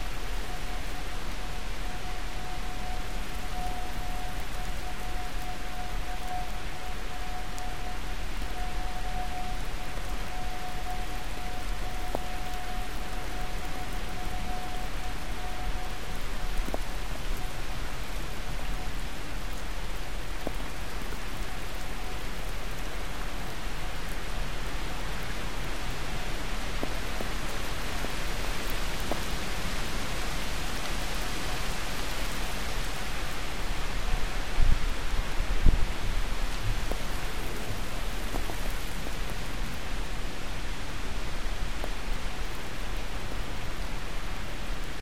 Unidentified noise 24/8/22 01:53